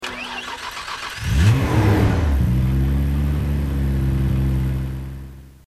Home gmod sound vehicles tdmcars skyliner34
enginestart.mp3